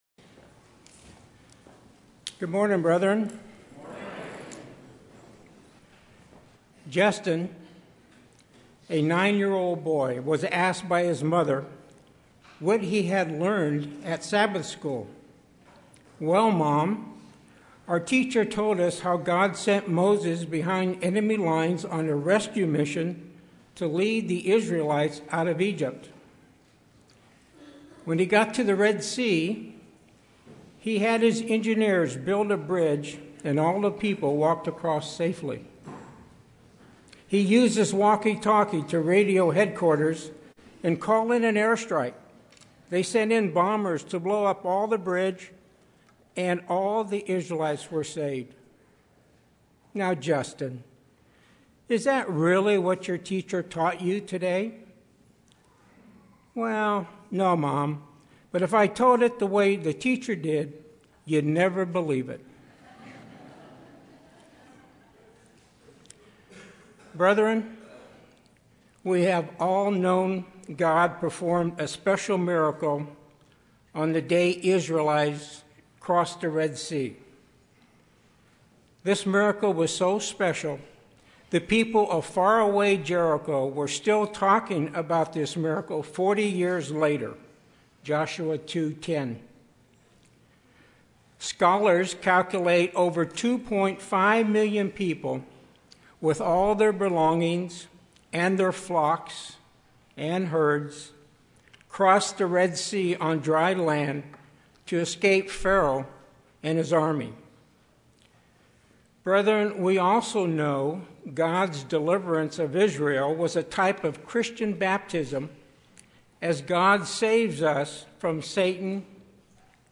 This sermon explores six important events and associated spiritual lessons from the Last Day of Unleavened Bread.